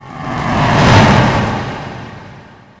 Descarga de Sonidos mp3 Gratis: cometa.
comet-effects.mp3